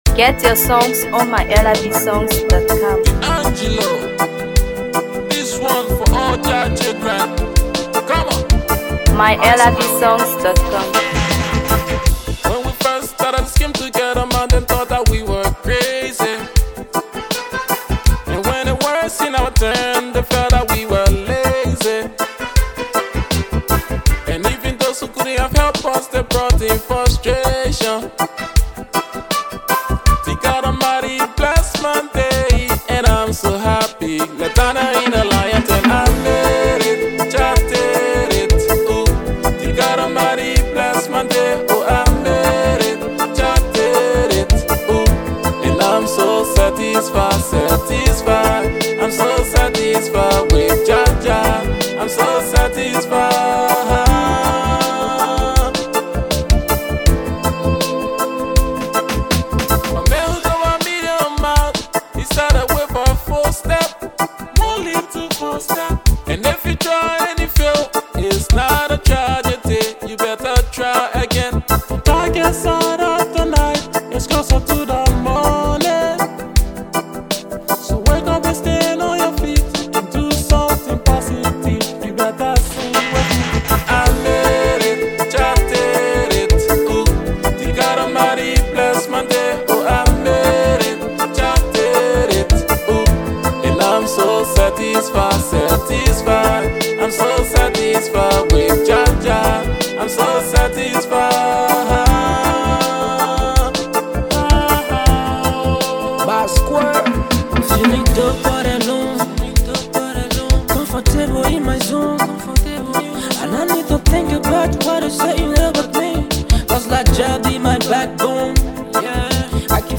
DancehallMusic
With smooth reggae vibes and powerful lyrics